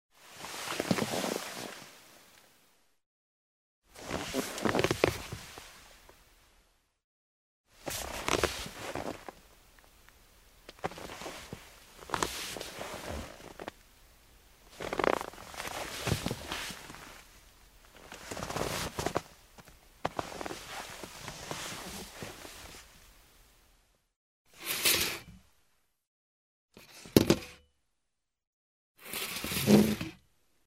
Скрип деревянного стула, его переносят, перемещают и садятся